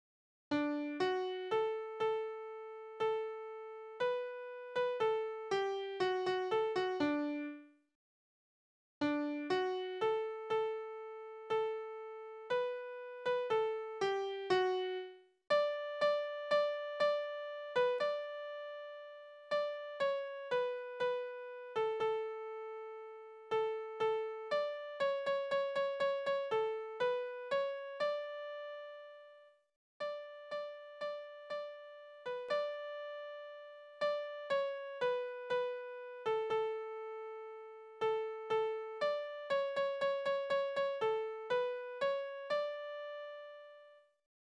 Soldatenlieder
Tonart: D-Dur
Taktart: 2/4
Tonumfang: Oktave
Besetzung: vokal